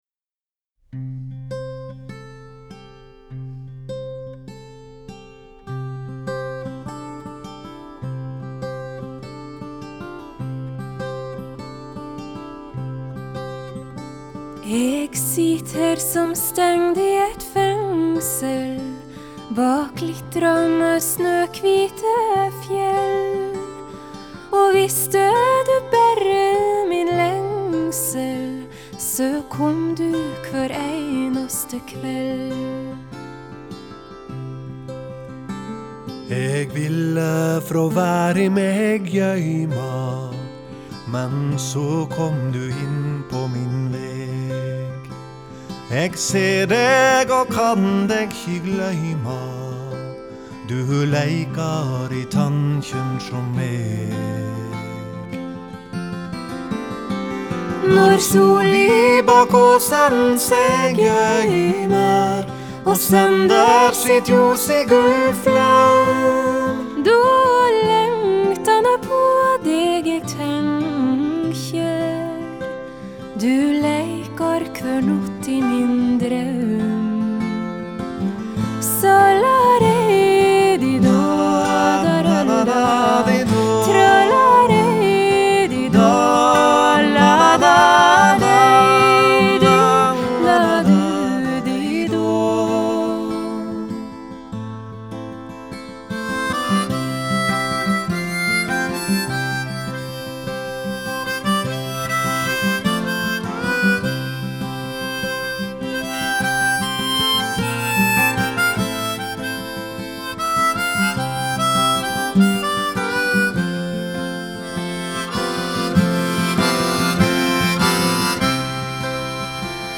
Genre: Folk-Rock, Folk-Pop, Folk
double dass, guitar
vocals, guitar
vocals, Guitar, banjo, harmonica, percussion, accordion